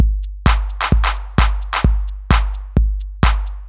R _ S Beat_130.wav